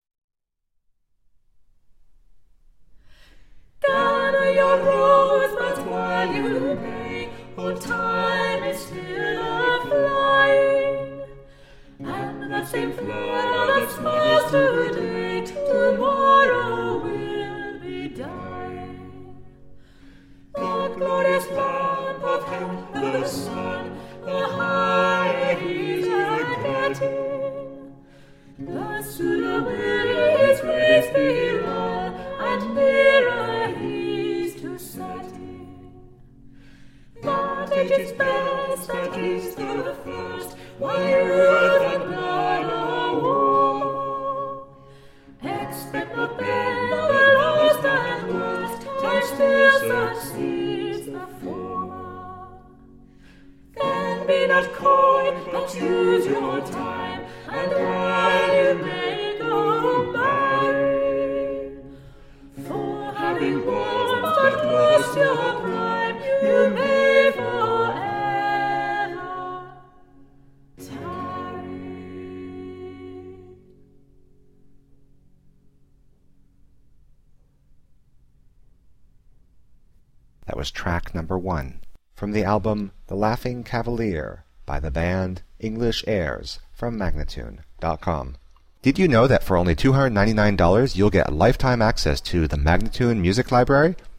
Traditional early english music.